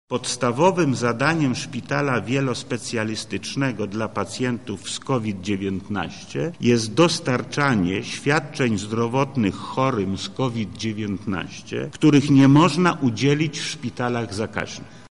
-tłumaczy Lech Sprawka.